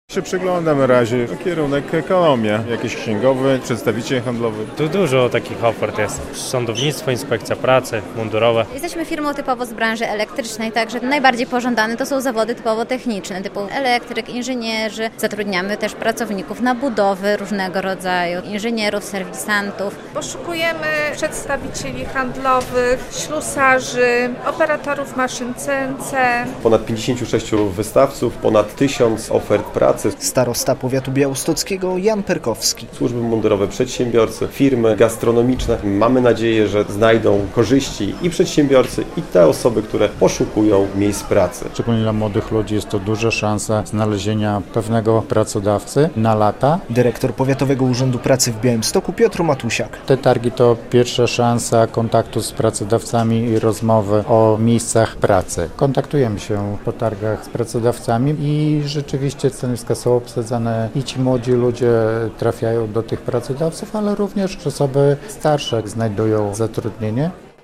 Targi pracy na Chorten Arenie w Białymstoku - relacja